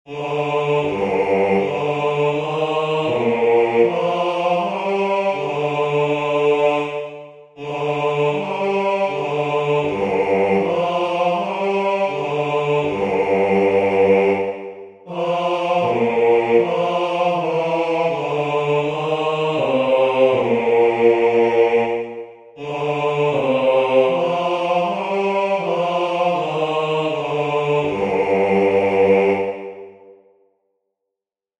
Skaņdarbs lieliski piemērots senās mūzikas praktizēšanai, visas balsis dzied vienā ritmā.
O.Salutaris-Bassus.mp3